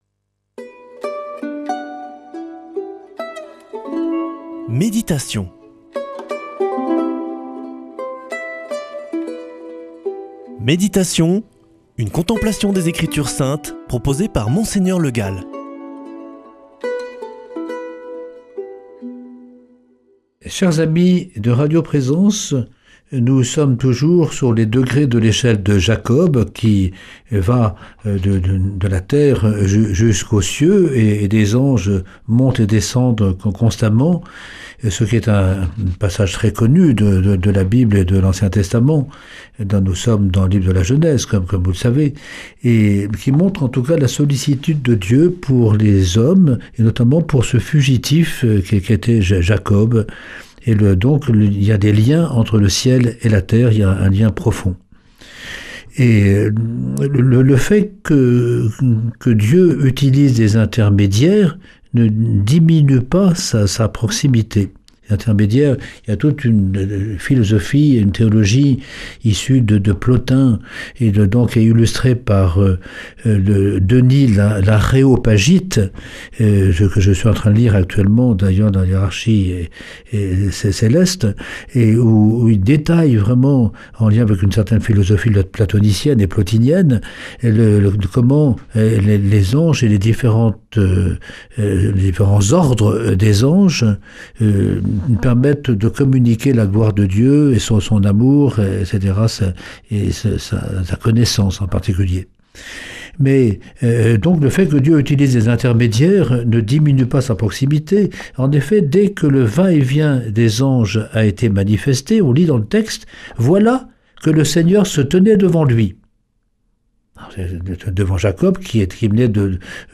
Méditation avec Mgr Le Gall
[ Rediffusion ] Majesté et proximité de Dieu